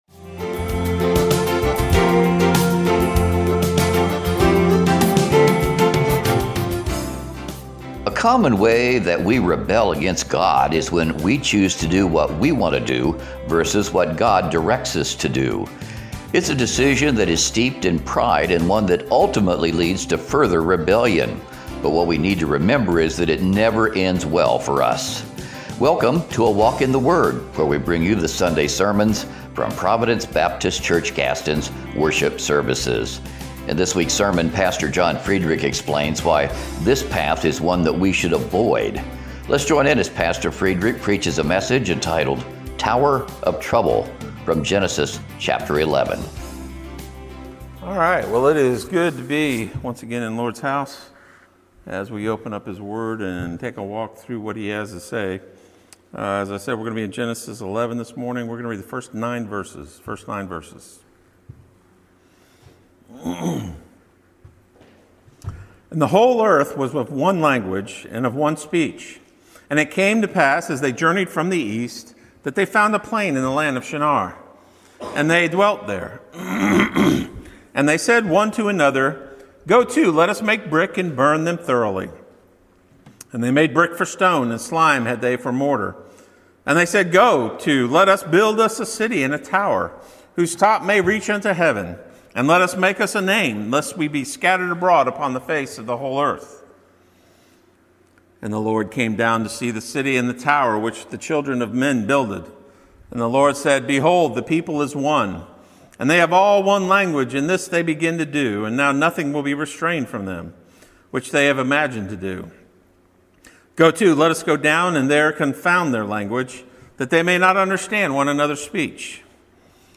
Recent sermons preached at Providence Baptist Church - Gaston